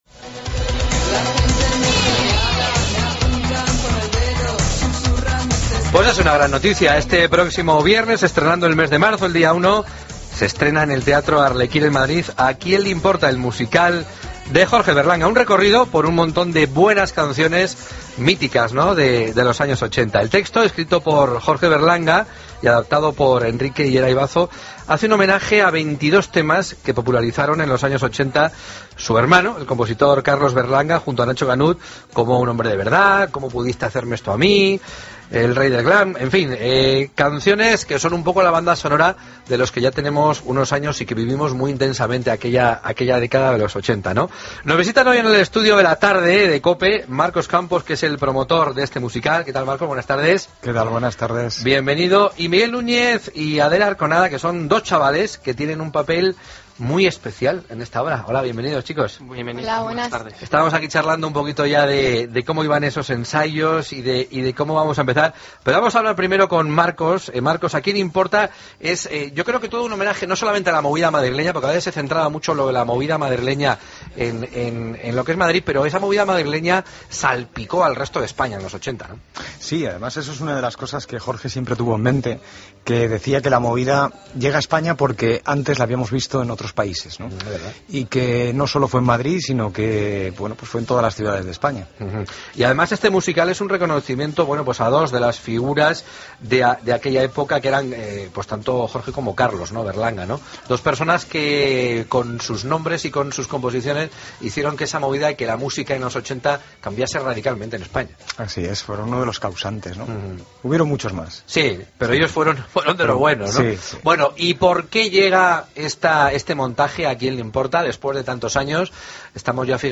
La Tarde Entrevista